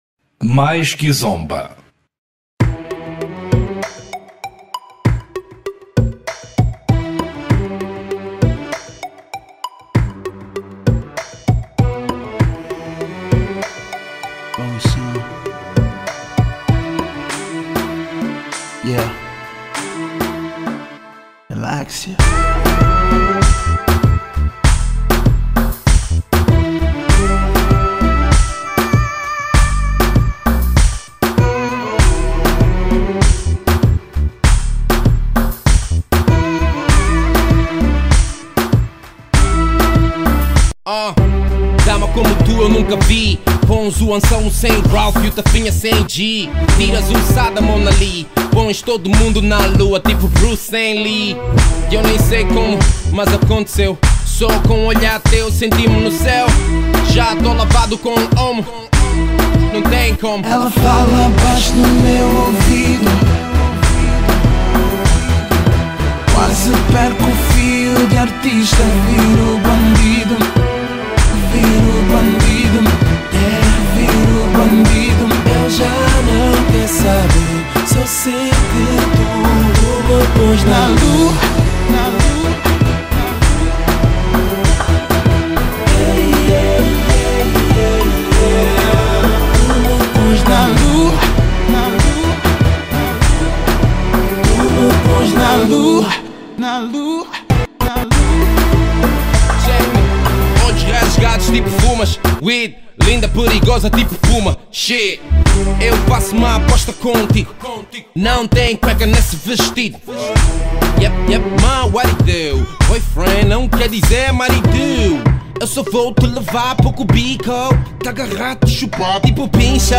R&B 2014